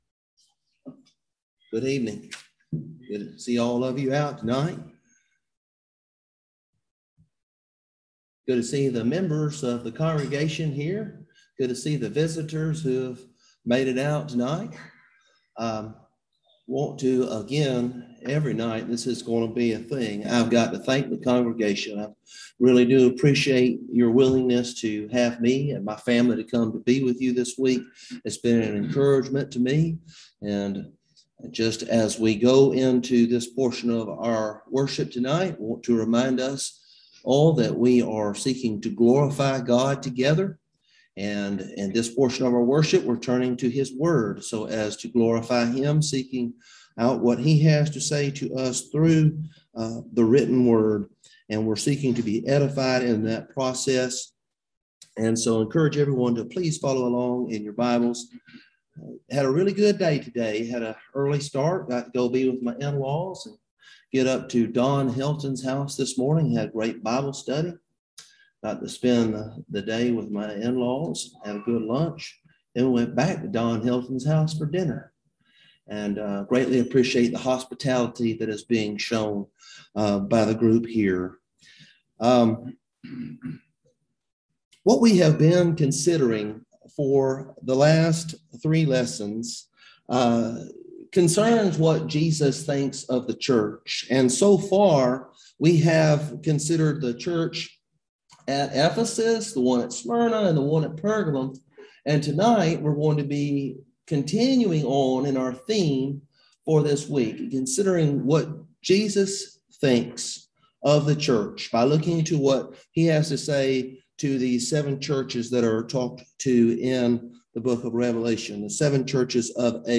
Passage: Revelation 2:18-29 Service Type: Gospel Meeting